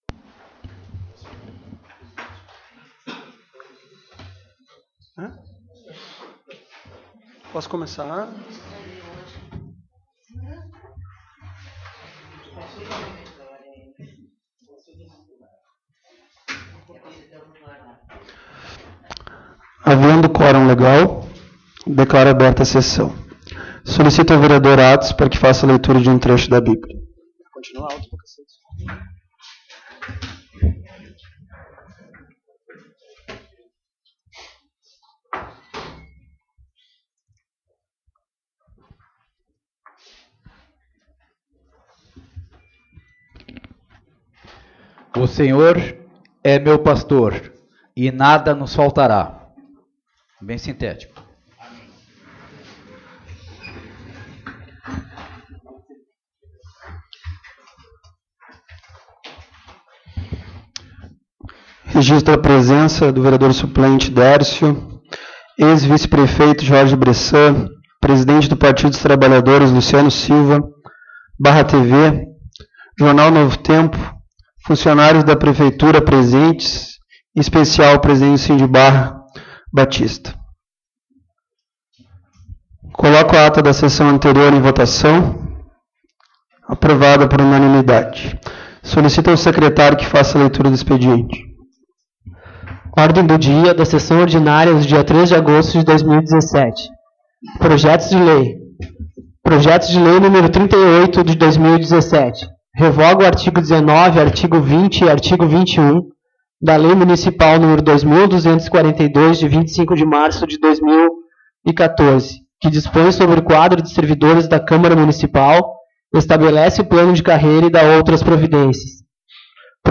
Publicação: 24/02/2021 às 11:06 Abertura: 24/02/2021 às 11:06 Ano base: 2017 Número: Palavras-chave: Anexos da publicação Áudio da Sessão Ordinária de 03.08.2017 às 19 h. 24/02/2021 11:06 Compartilhar essa página...